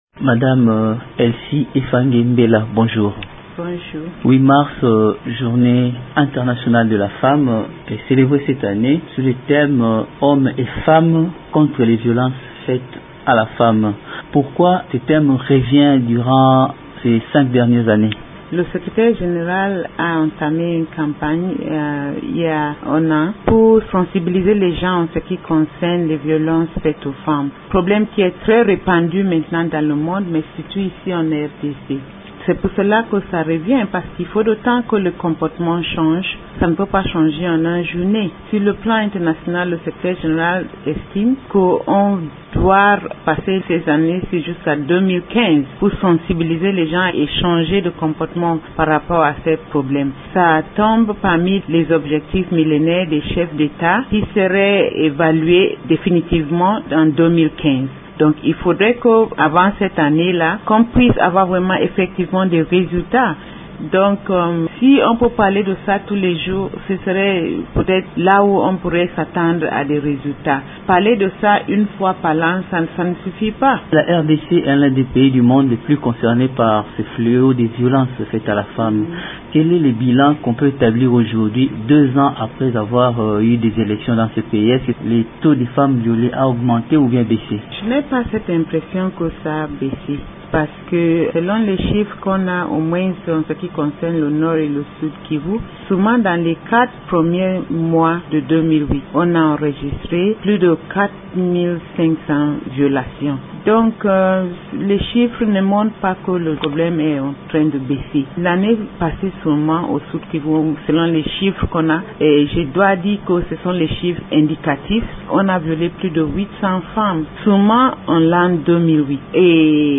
Elle est interrogée